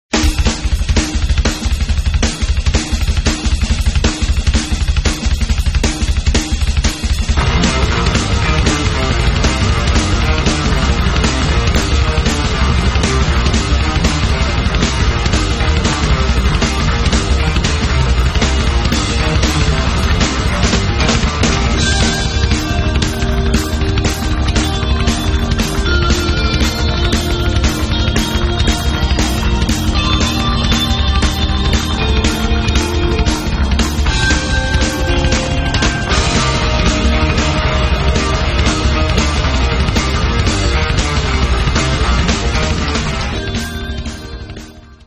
Fusion
Jazz
Rock